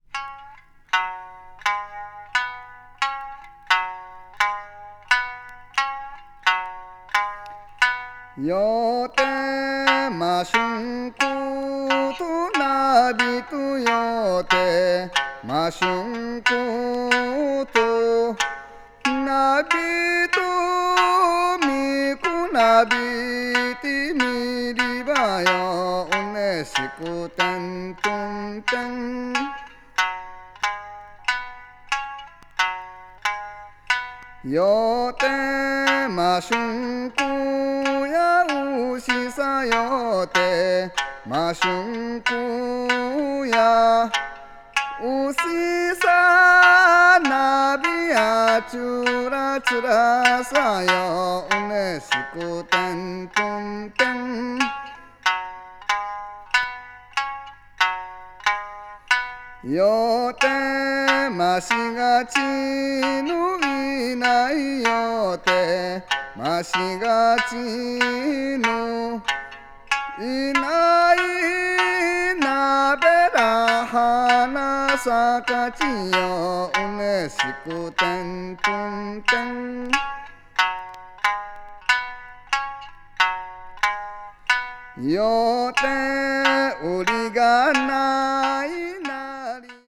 録音も非常に秀逸。
ethnic music   japan   minyo   okinawa   ryukyu   traditional